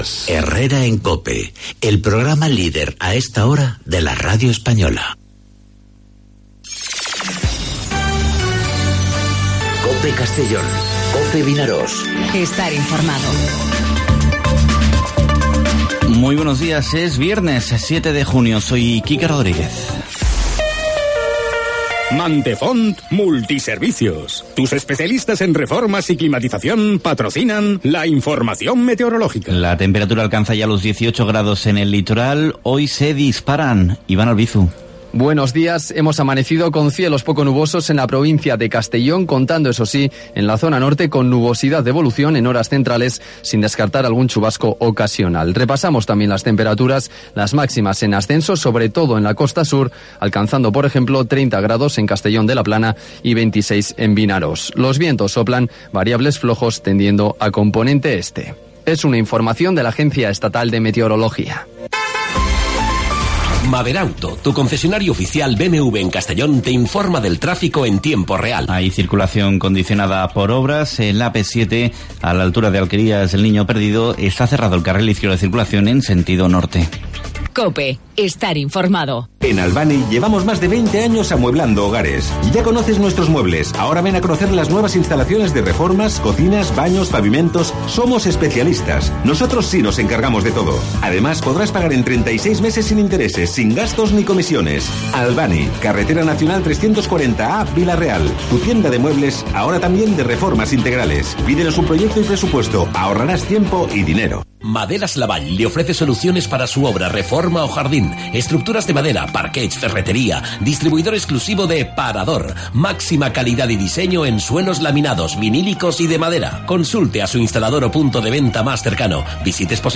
Informativo 'Mediodía COPE' en Castellón (07/06/2019)